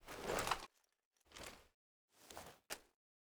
4aef571f59 Divergent / mods / Tommy Gun Drop / gamedata / sounds / weapons / thompson / m1a1_new_inspect.ogg 78 KiB (Stored with Git LFS) Raw History
m1a1_new_inspect.ogg